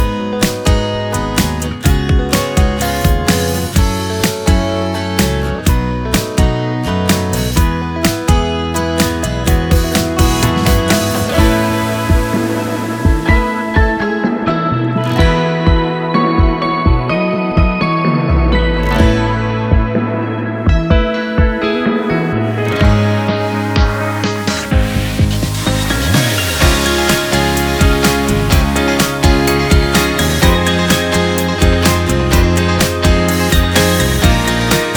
Жанр: Поп / K-pop